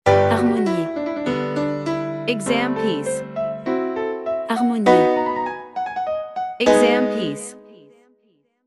• 人声数拍
我们是钢琴练习教材专家